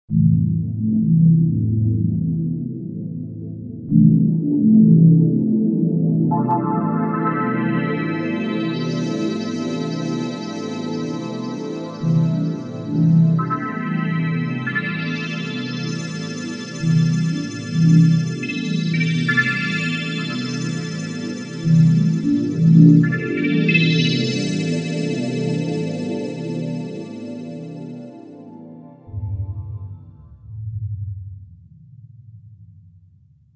LFO noises